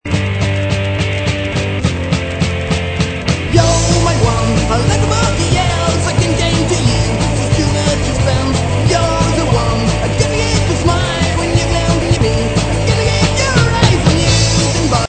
des musiques auto-produites
punk-rock